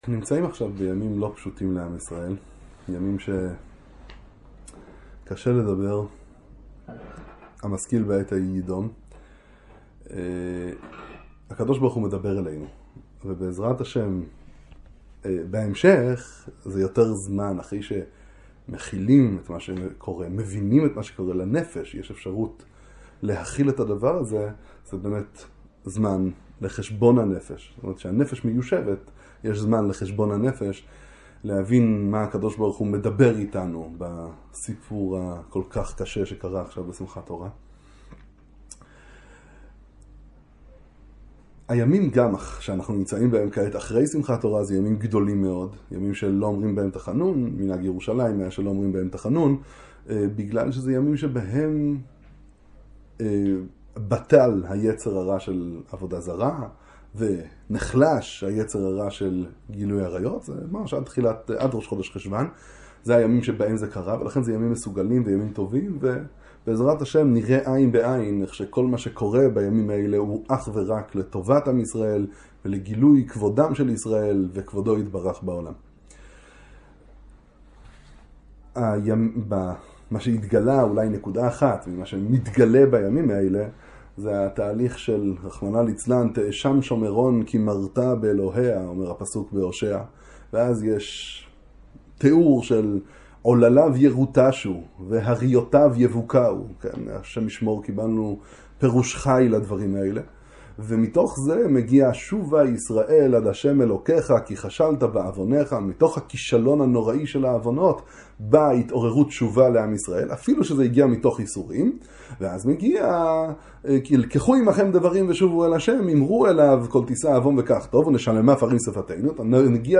דברי חיזוק בשעת מלחמה